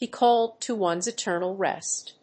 アクセントbe cálled to one's etérnal rést